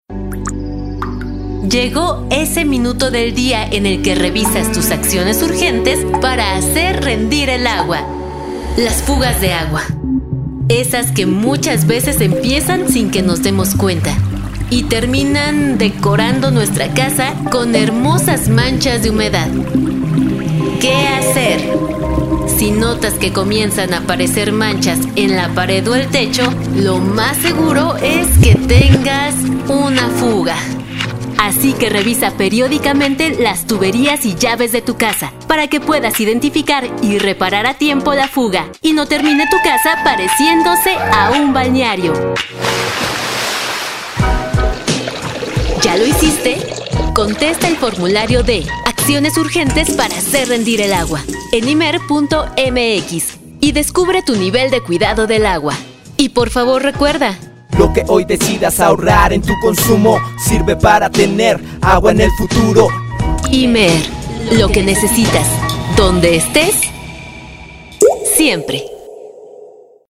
ACTUACIÓN DRAMÁTICA